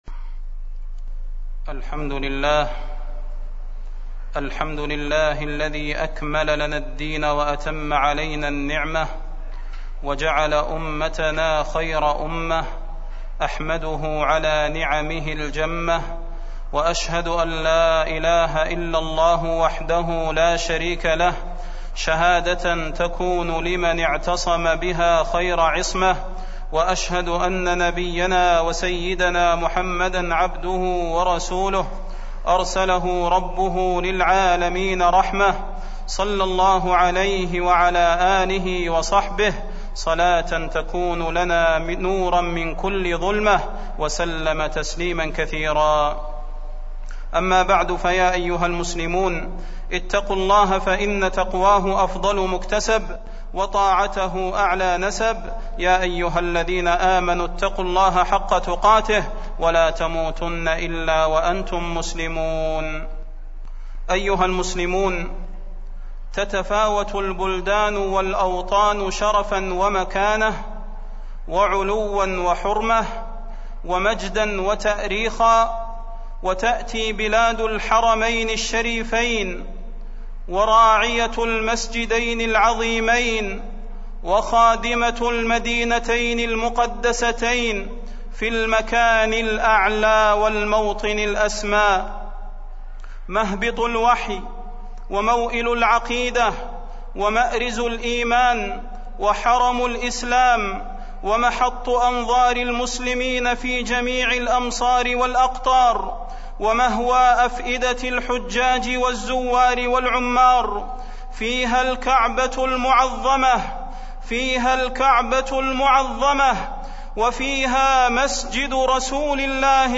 فضيلة الشيخ د. صلاح بن محمد البدير
تاريخ النشر ١٤ رجب ١٤٢٦ هـ المكان: المسجد النبوي الشيخ: فضيلة الشيخ د. صلاح بن محمد البدير فضيلة الشيخ د. صلاح بن محمد البدير طاعة ولي الأمر